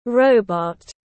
Người máy tiếng anh gọi là robot, phiên âm tiếng anh đọc là /ˈrəʊ.bɒt/
Robot /ˈrəʊ.bɒt/